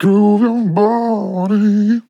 DISCO VIBES
Categories: Vocals
man-disco-vocal-fills-120BPM-Fm-2.wav